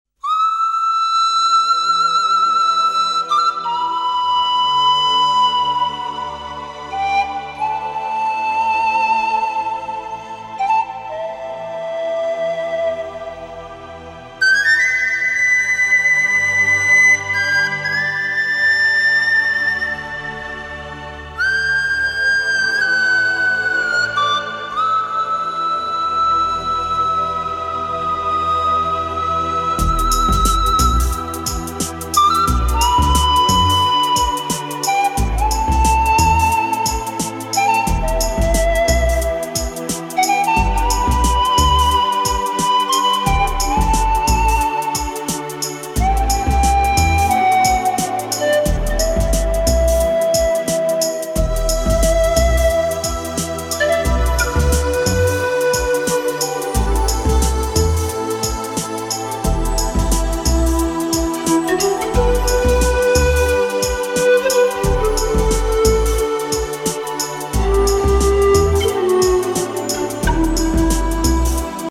• Качество: 224, Stereo
свист
красивые
спокойные
без слов
инструментальные
свирель